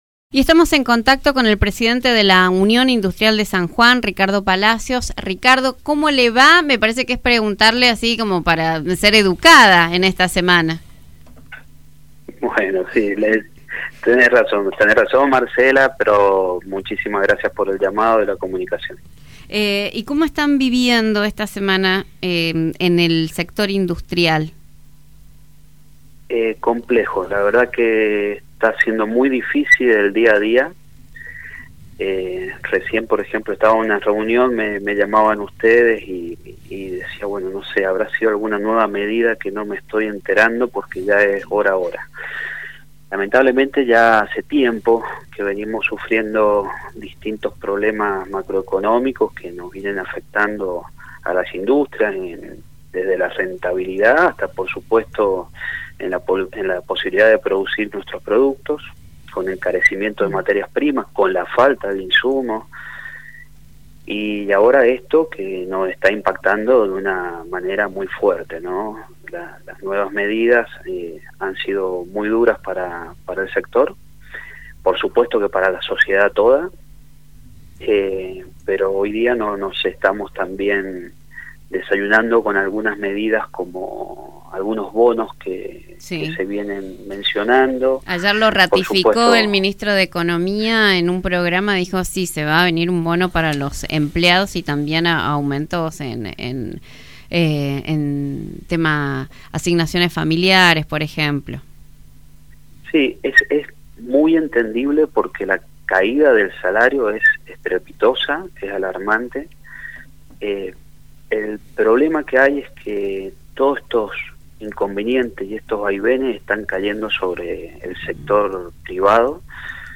en comunicación con Radio Sarmiento